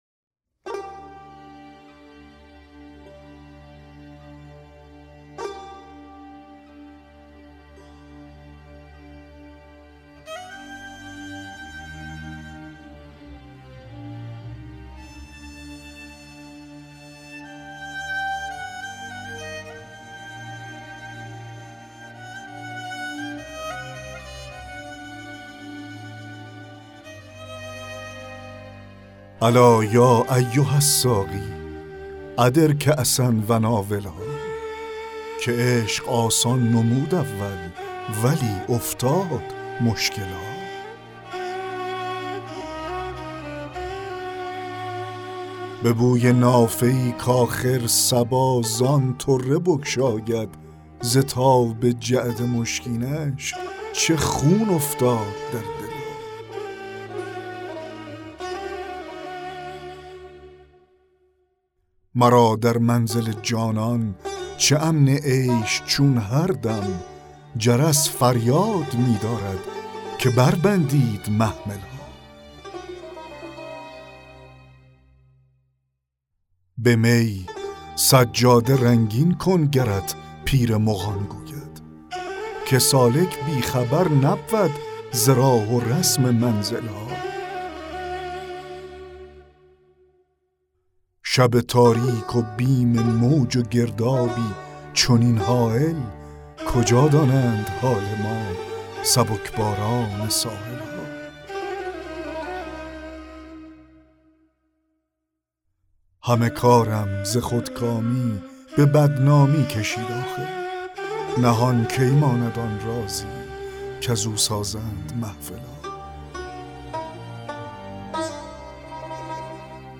دکلمه غزل 1 حافظ